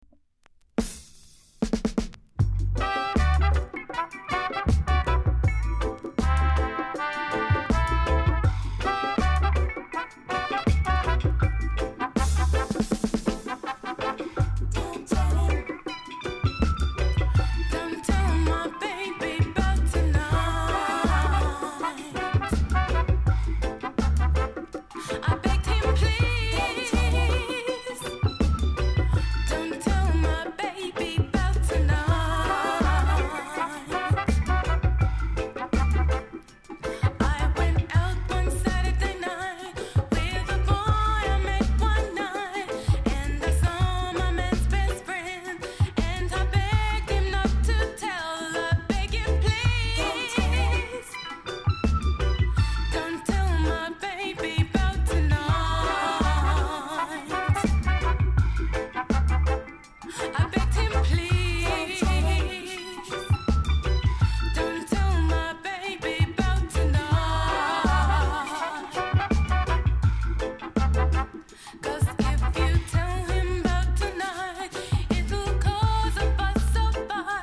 ※序盤に小傷ありノイズが少しあります。
コメント LOVERS CLASSIC!!